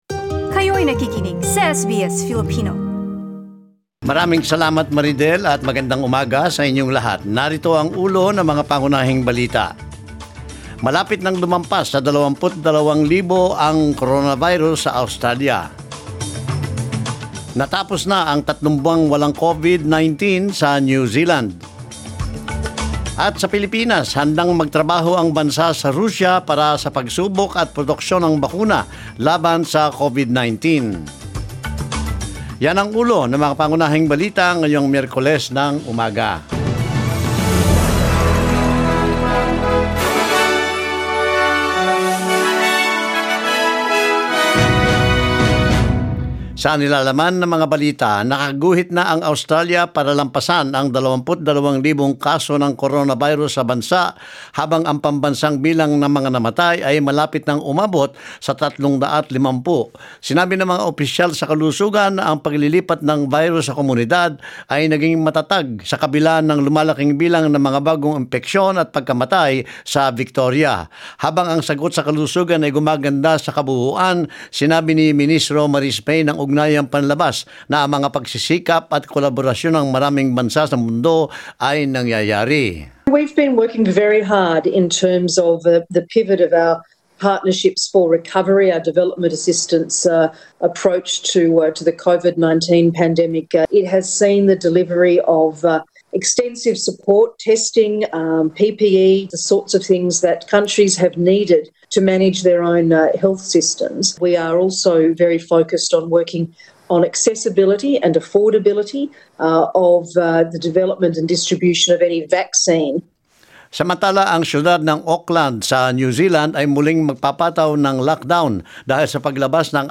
Mga balita ngayong ika 12 ng Agosto